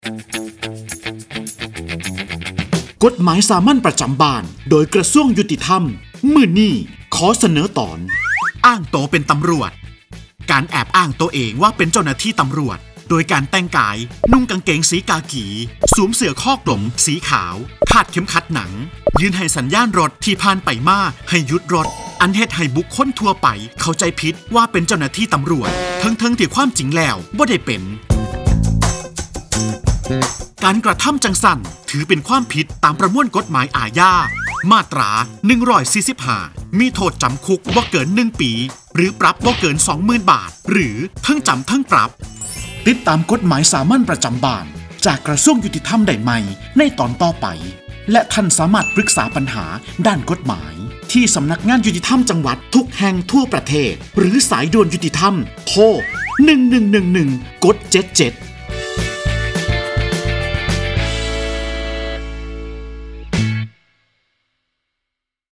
ชื่อเรื่อง : กฎหมายสามัญประจำบ้าน ฉบับภาษาท้องถิ่น ภาคอีสาน ตอนอ้างตัวเป็นตำรวจ
ลักษณะของสื่อ :   บรรยาย, คลิปเสียง